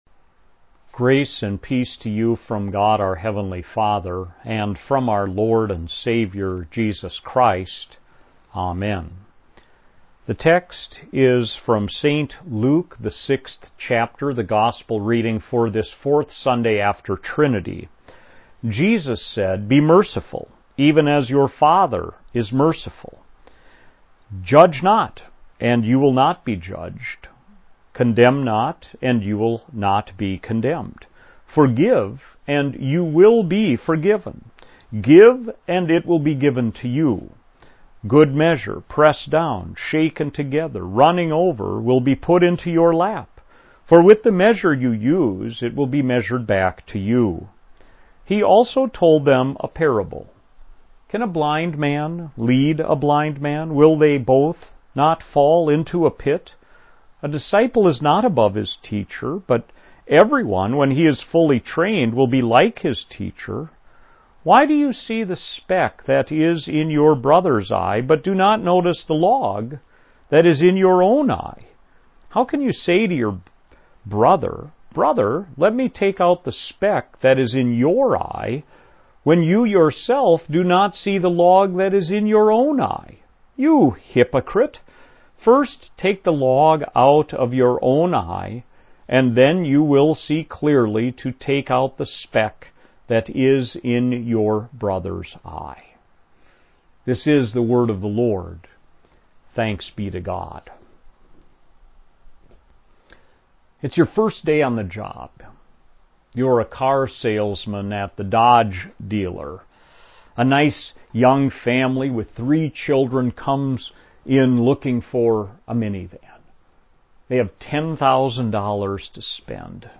2020 Trinity 4 Sermon – Redeemer Evangelical Lutheran Church